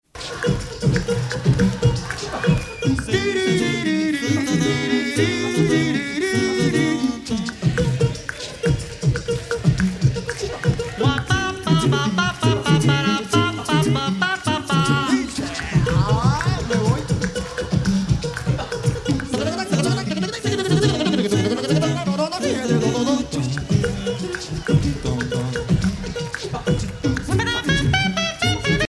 Extrait d'une polyphonie de chanteurs africains